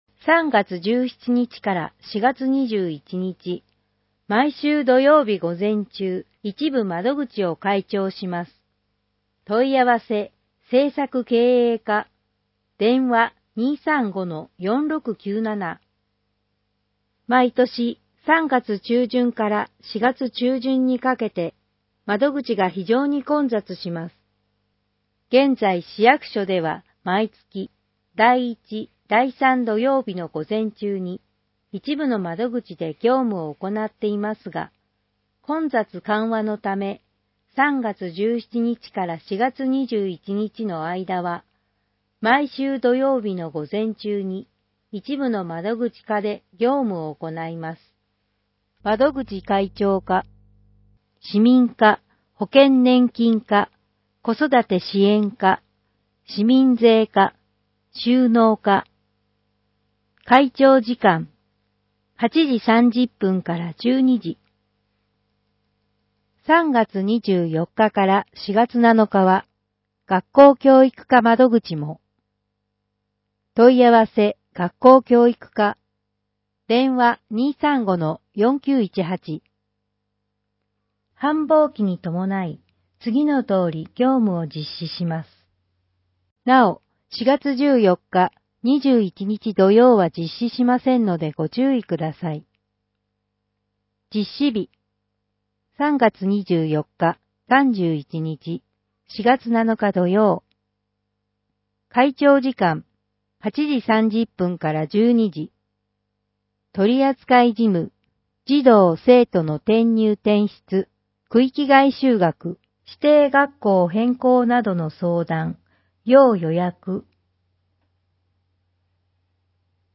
※音声版は、音声訳ボランティア「矢ぐるまの会」の協力により、同会が視覚障がい者の方のために作成したものを、順次搭載します。